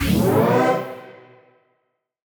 FR_T-PAD[up]-C.wav